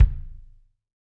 Kick Zion 3.wav